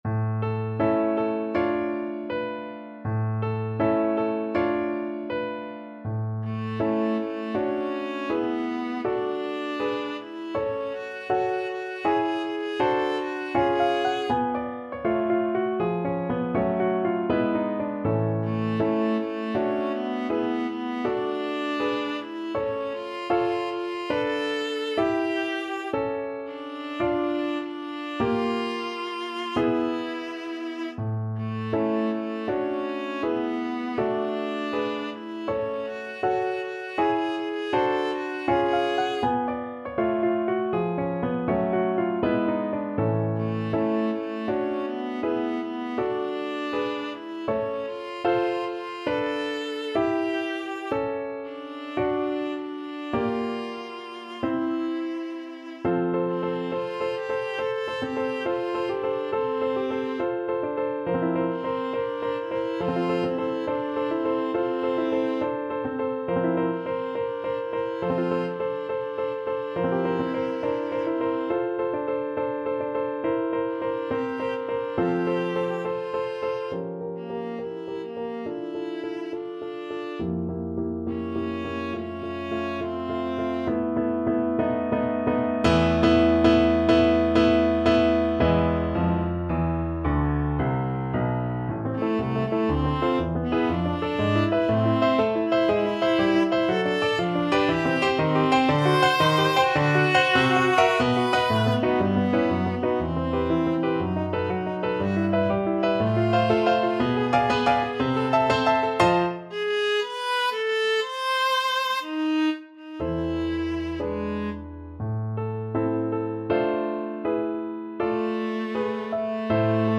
2/4 (View more 2/4 Music)
Moderato =80
Classical (View more Classical Viola Music)